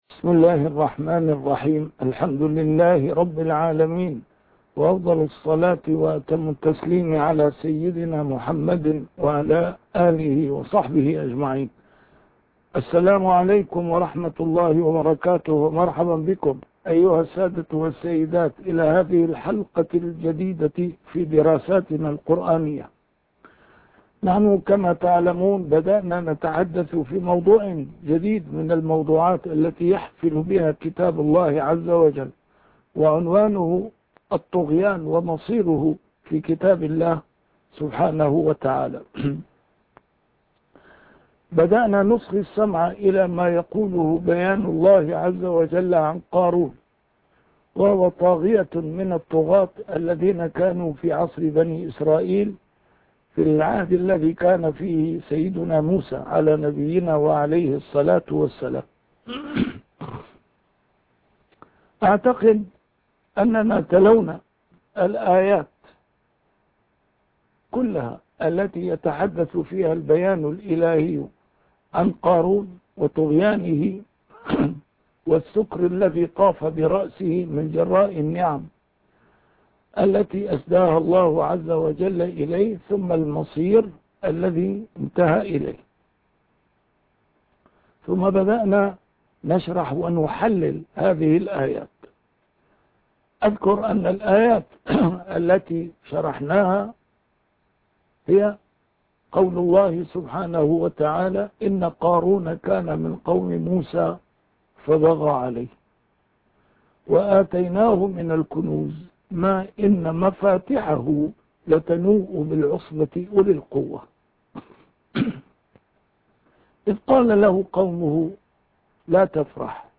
A MARTYR SCHOLAR: IMAM MUHAMMAD SAEED RAMADAN AL-BOUTI - الدروس العلمية - درسات قرآنية الجزء الثاني - 2- الإفساد في القرآن الكريم